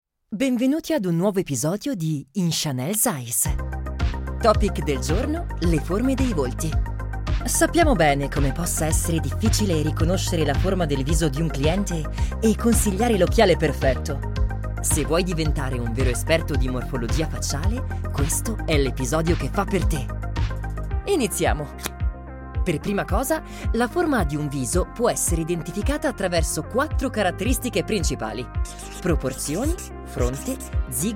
Kommerziell, Cool, Vielseitig, Freundlich, Warm
E-learning